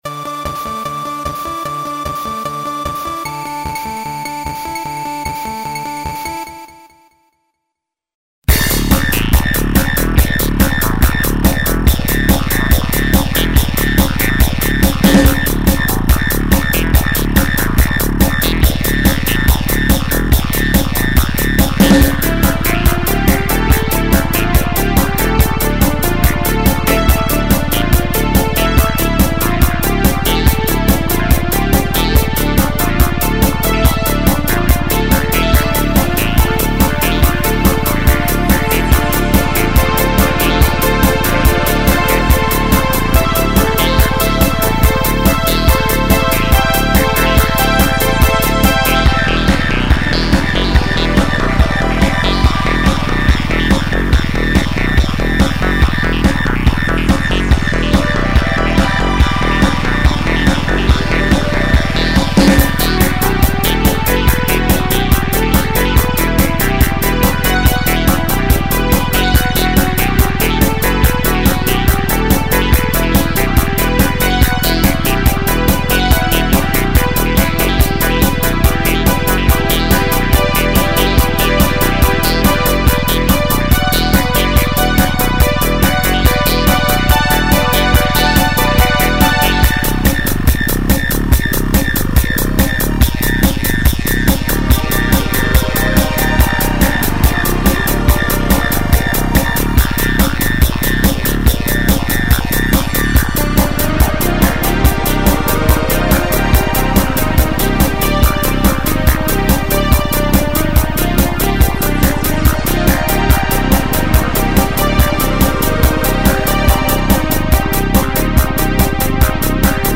Spectral audio Protone